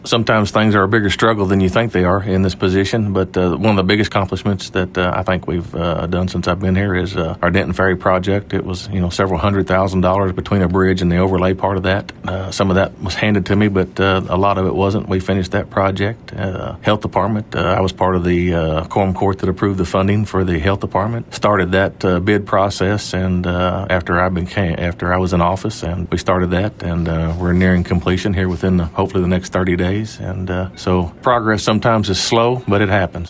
KTLO News caught up with the Judge to discuss the upcoming campaign and his motives for seeking re-election.